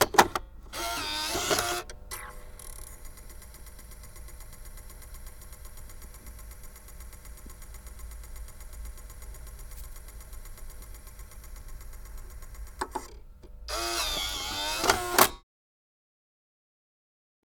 На этой странице собраны звуки магнитофона: шум ленты, щелчки кнопок, запись с кассет.